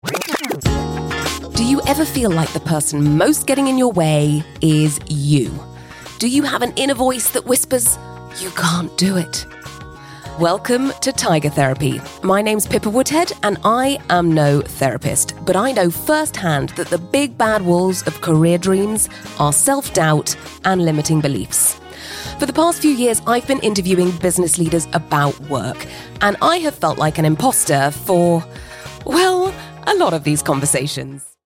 Voice Samples: Podcast Host Sample 02
female